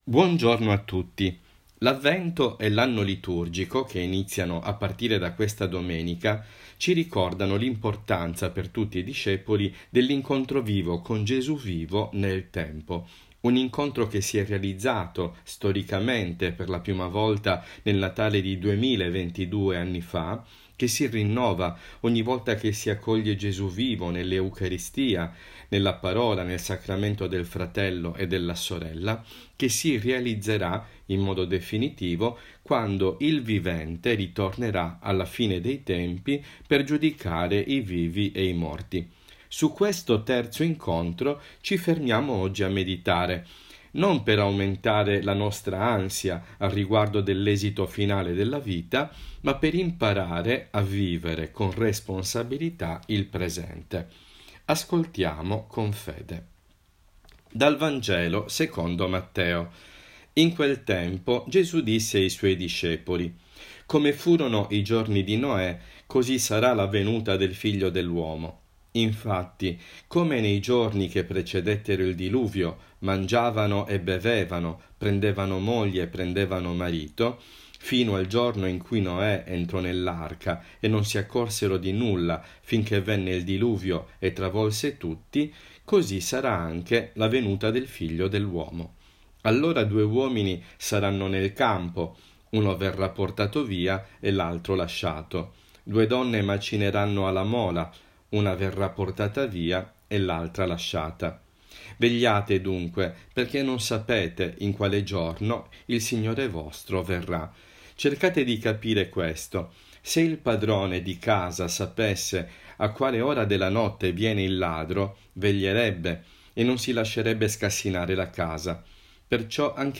Meditazione I di Avvento – 27 novembre 2022